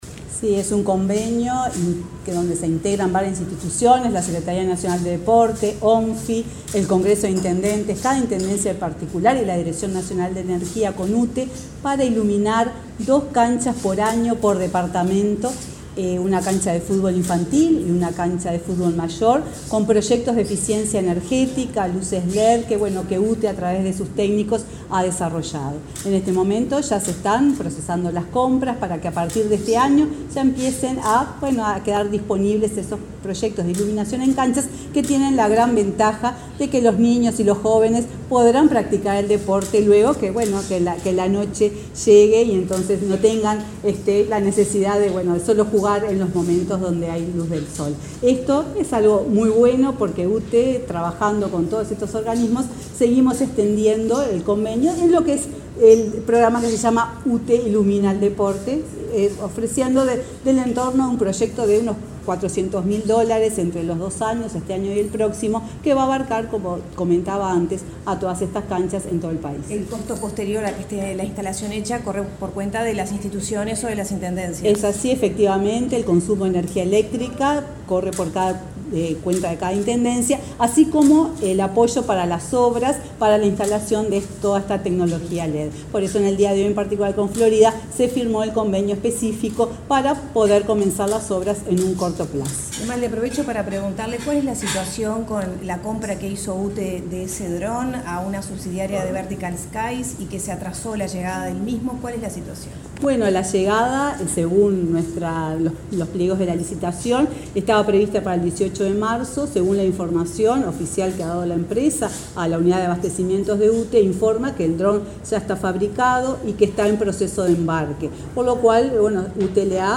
Declaraciones de la presidenta de UTE, Silvia Emaldi
La presidenta de la UTE, Silvia Emaldi, dialogó con la prensa, luego de firmar un convenio con el intendente de Florida, Guillermo López, para